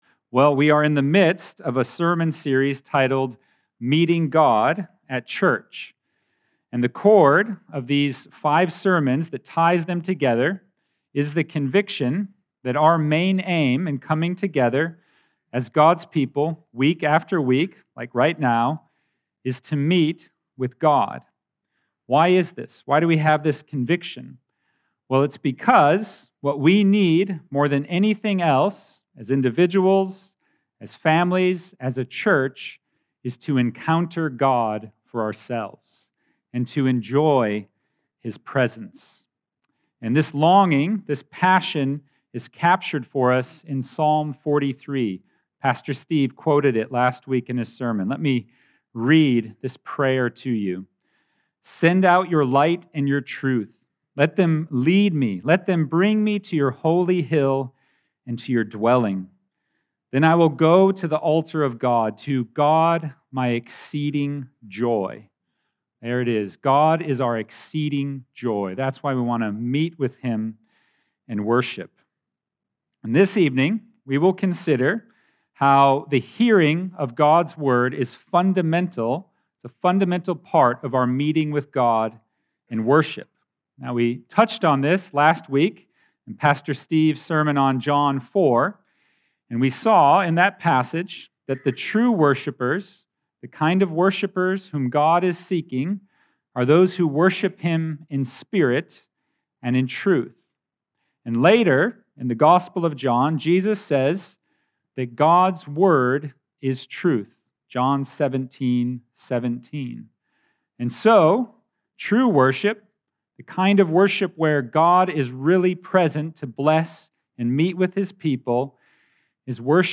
Grace Church Sermons podcaster